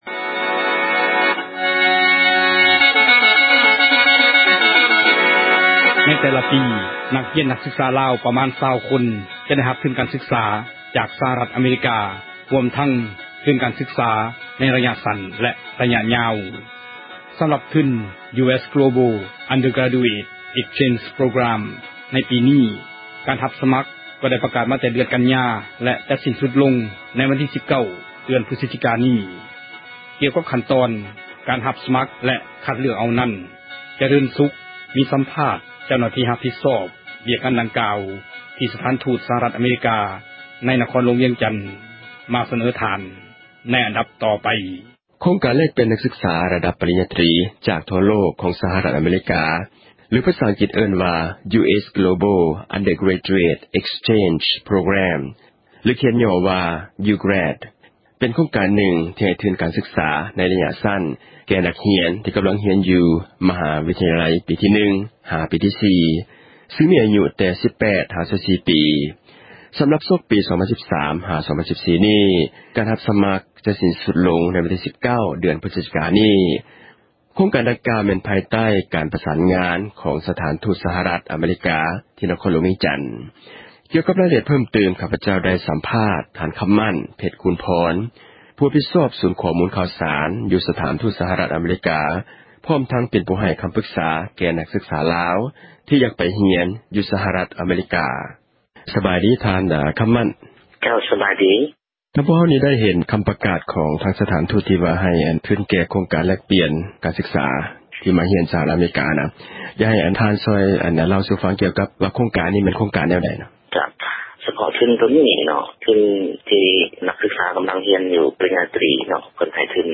ມີສໍາພາດ